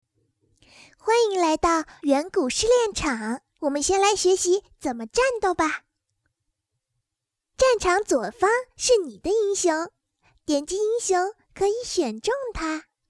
女声
王者荣耀角色模仿-9貂蝉